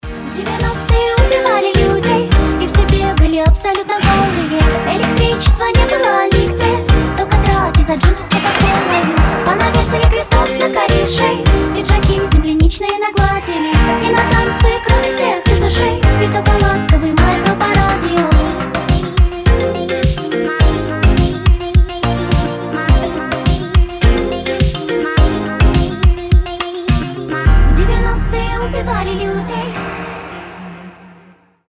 • Качество: 320, Stereo
милые
веселые
красивый женский голос
teen pop